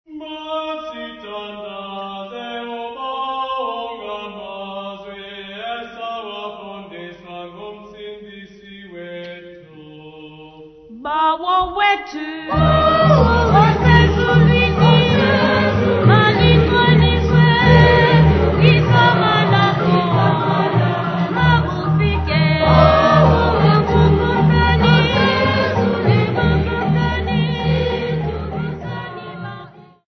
Folk music
Folk songs, Xhosa
Church music
Field recordings
Men and women perform at the Kwazakhele Music Workshop.
7.5 inch reel
96000Hz 24Bit Stereo